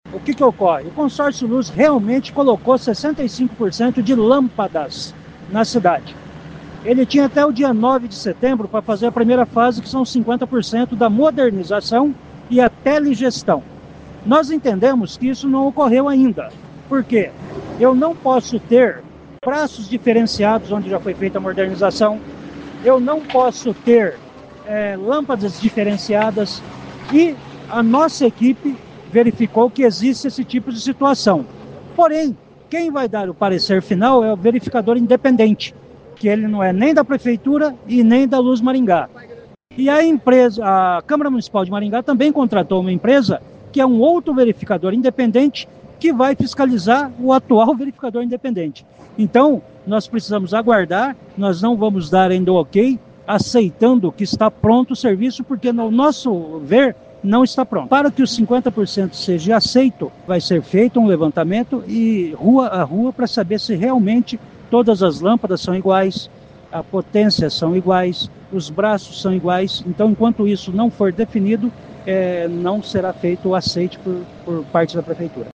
Ouça o que diz o secretário Vagner Mussio.